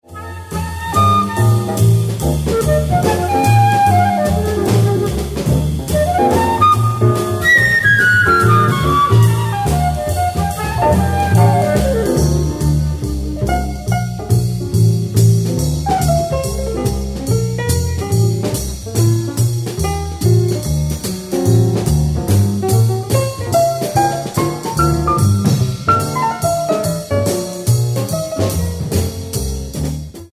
tenor saxophone, flute and vibes